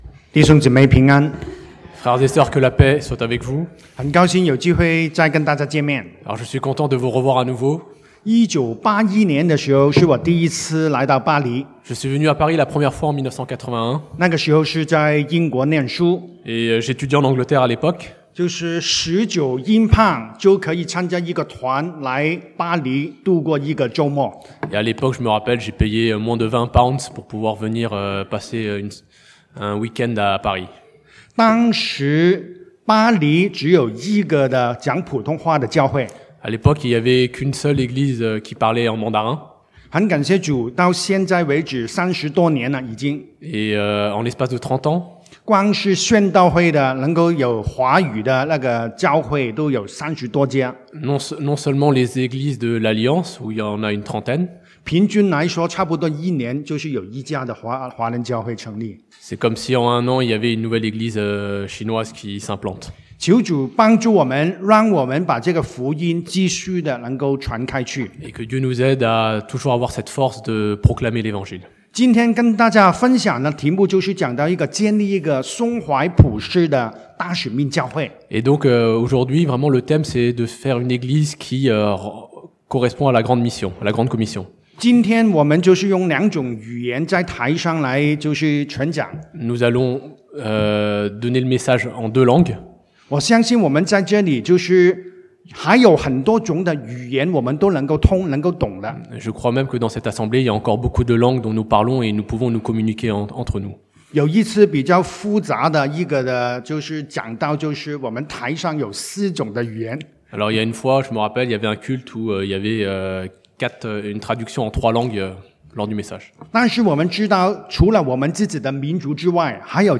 (chinois traduit en français)